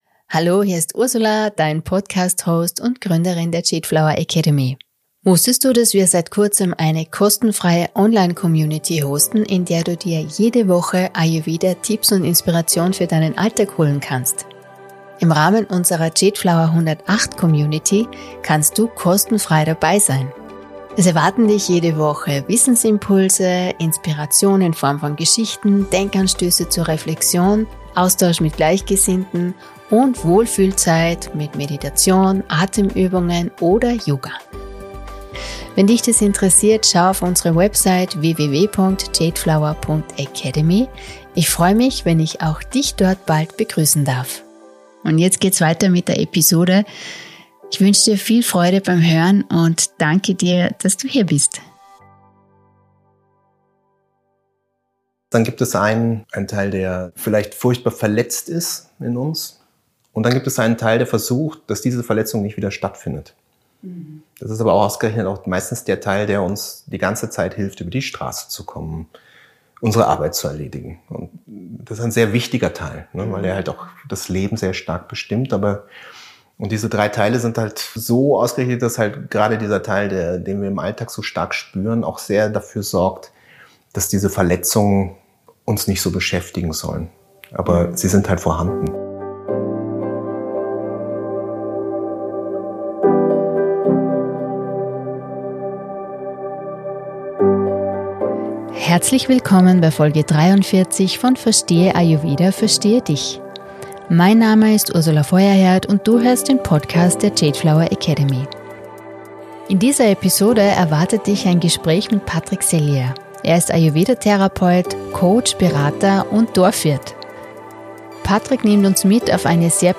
Ein Gespräch über den Mut zur Ehrlichkeit, den Körper als Wegweiser und die Kraft der eigenen Identität.